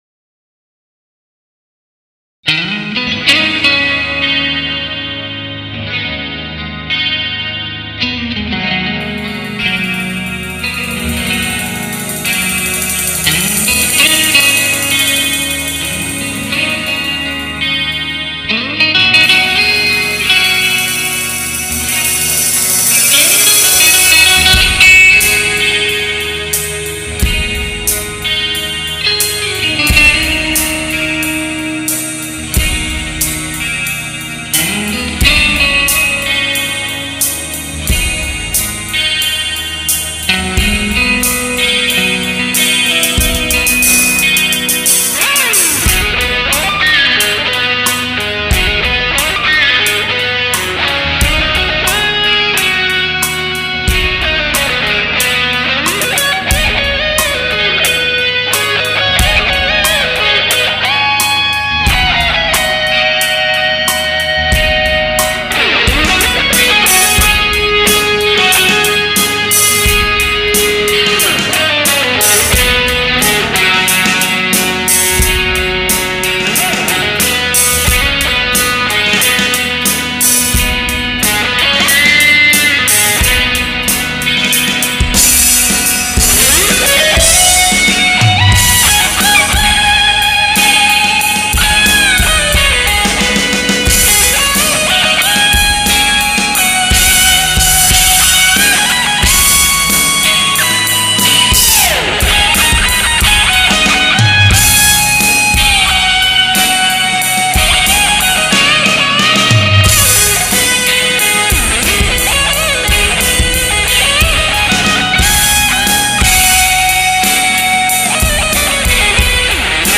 Guiter